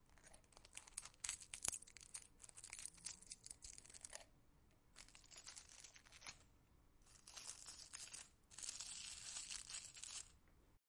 干叶
描述：破碎干叶和死花瓣。